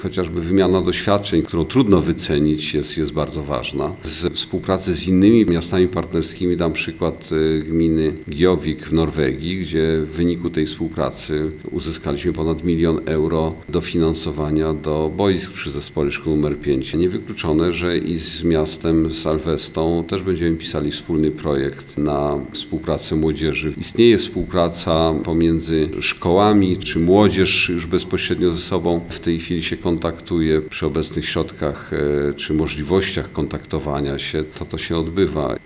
Burmistrz Jakubiec zaznacza, że współpraca z miastami partnerskimi przynosi nie tylko korzyści w postaci wymiany grup młodzieżowych czy poznawania historii i kultury partnerskich miast: